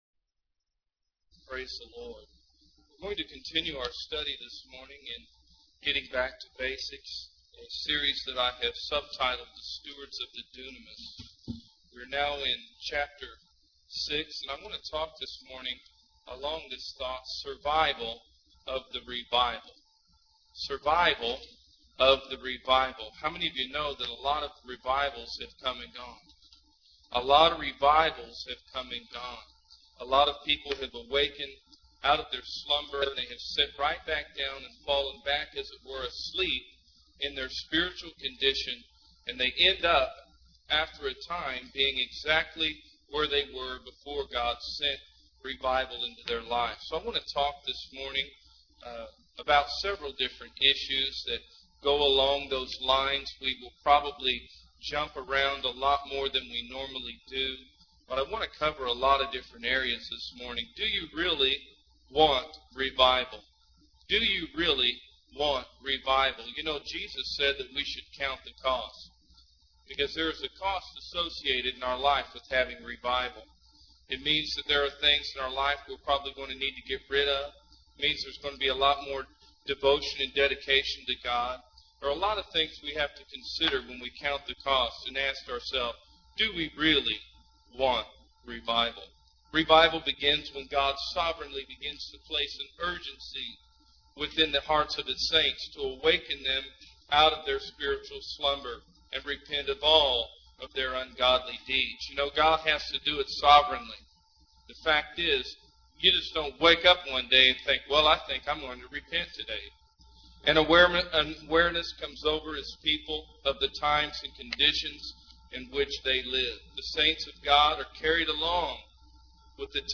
In this sermon, the speaker discusses the concept of revival and how many people experience temporary awakenings but eventually fall back into their old spiritual condition. He emphasizes the importance of not becoming lazy or seeking alternative entertainment for our spiritual walk with God, as this can lead to trouble.